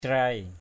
/craɪ/ (đg.) tưới rải. crai aia ka njam =c a`% k% W’ tưới nước cho rau.
crai.mp3